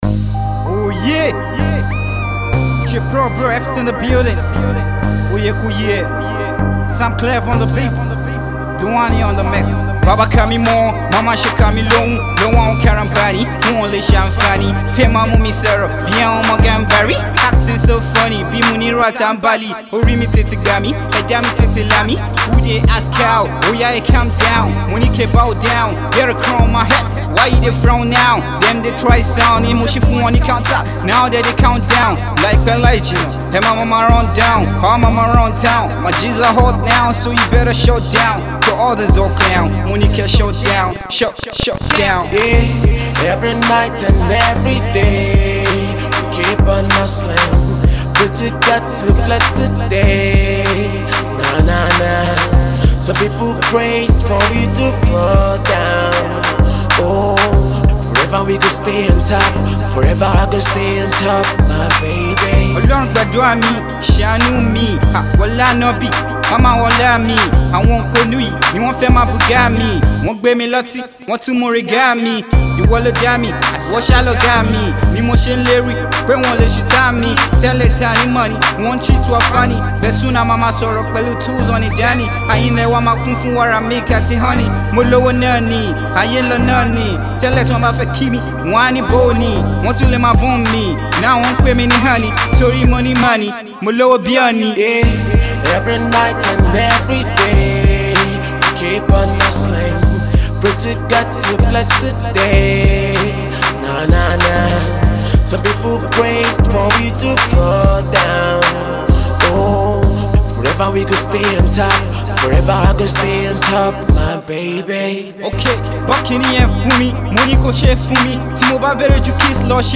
indigenous Hip-Hop, Rap